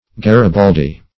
Garibaldi \Ga`ri*bal"di\, n.